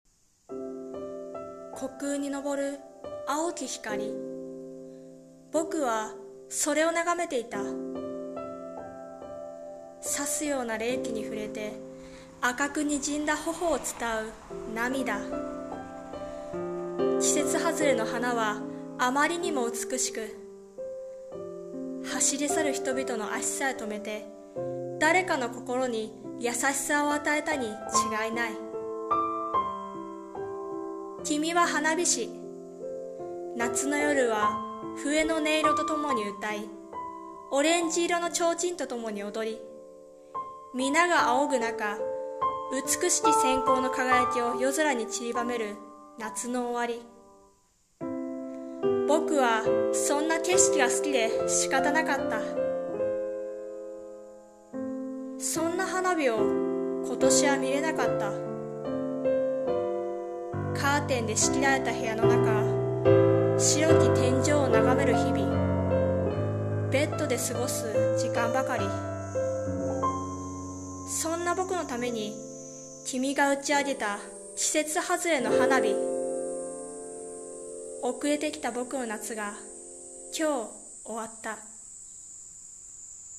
さんの投稿した曲一覧 を表示 声劇【季節外れの花火】※友情声劇